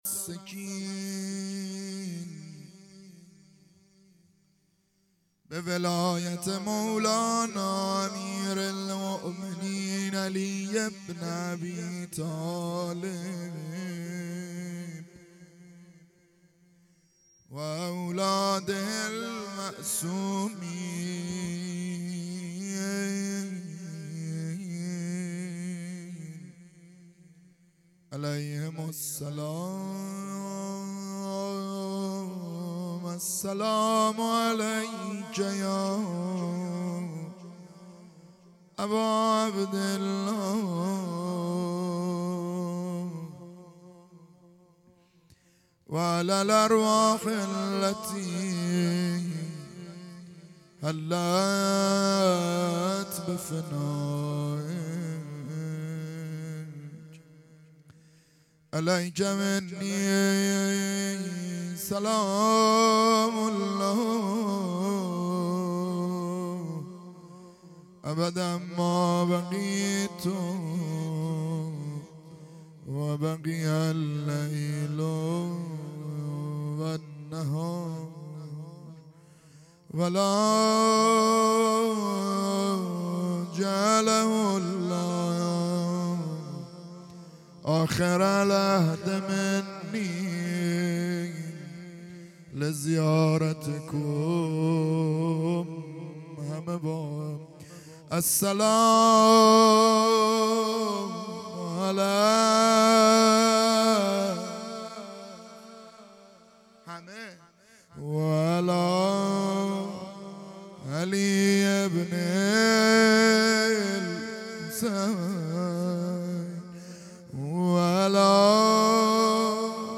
عزاداری دهه اول محرم الحرام 1442